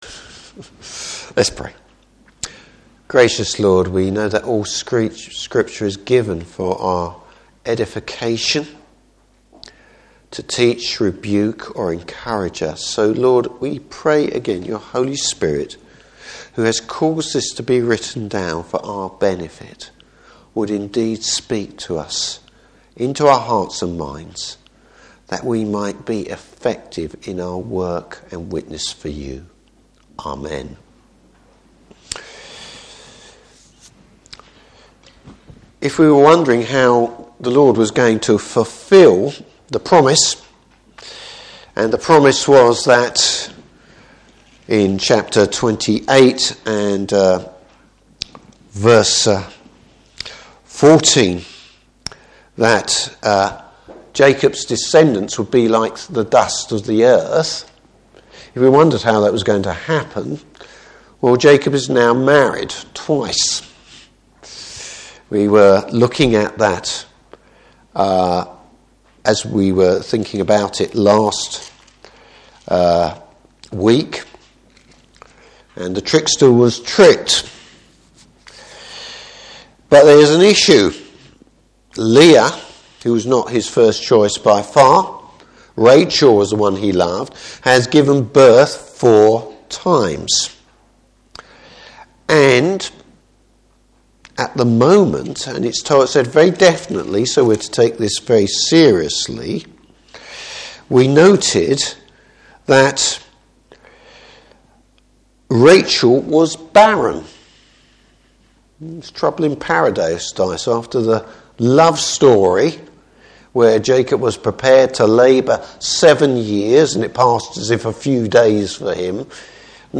Service Type: Evening Service Rachel learns you cannot manipulate the Lord!